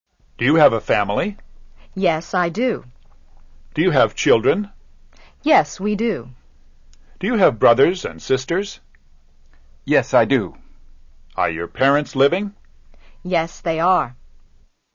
はつおんれんしゅうEnglish USA Lesson 18　Part 1-2